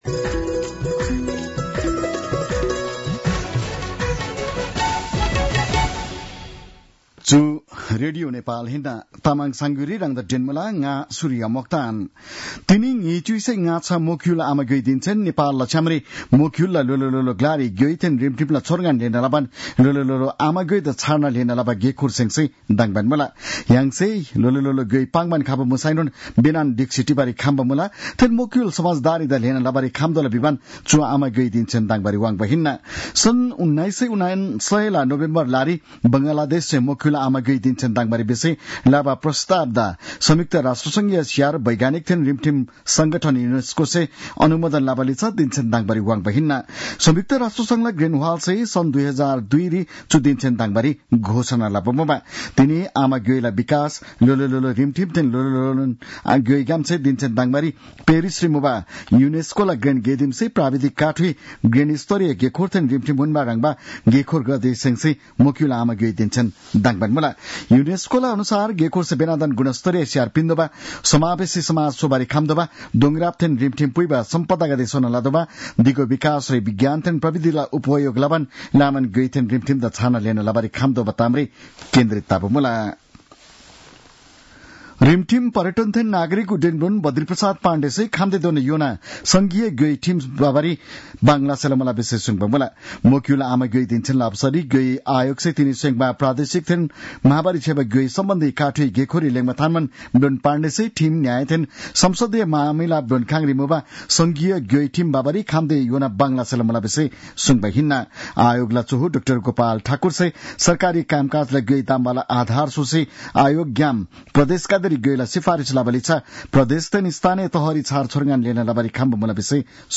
तामाङ भाषाको समाचार : १० फागुन , २०८१